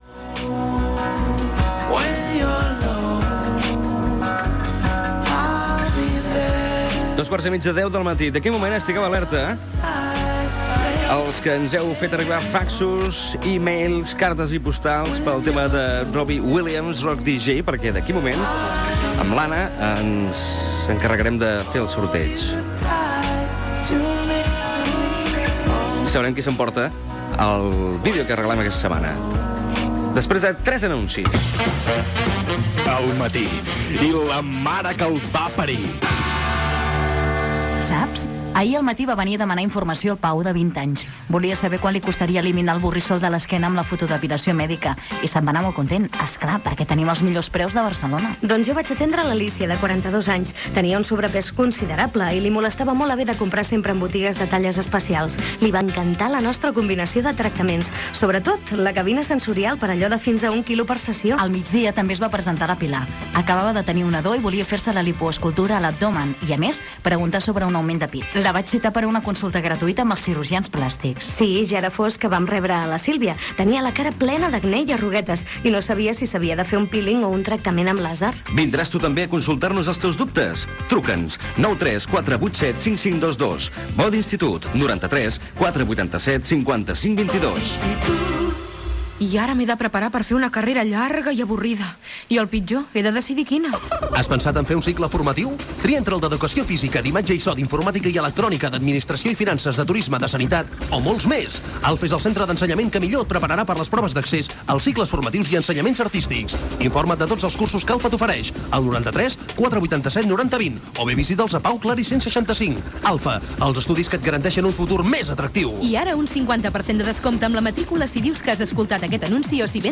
Hora,indicatiu, publicitat, concurs de Flaixbac, hora, sorteig d'un equip de vídeo entre els participants i trucada a l'oïdor guanyador, indicatiu, publicitat, indicatiu, repàs a les estrenes de cinema, trucades per tenir una invitació per anar al cinema, crònica des del Festival de Cinema de Donòstia, comiat
Entreteniment